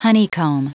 Prononciation du mot honeycomb en anglais (fichier audio)